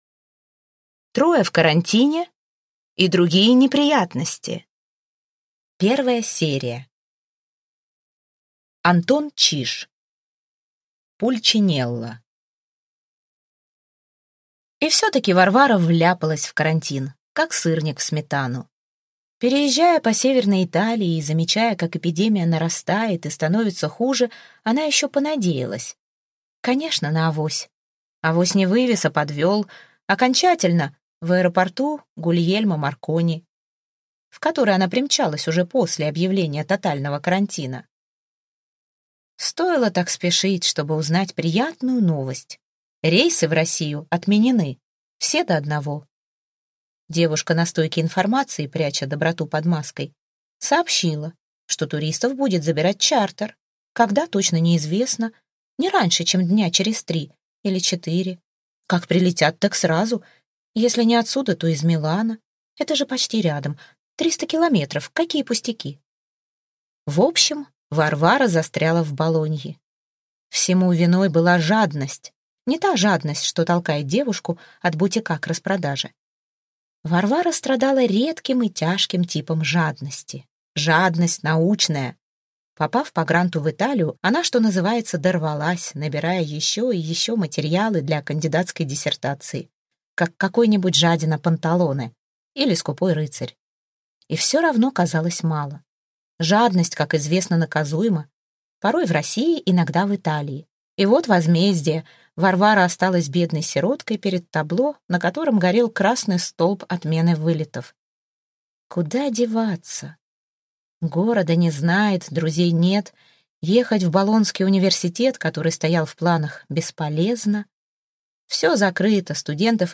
Аудиокнига Трое в карантине и другие неприятности | Библиотека аудиокниг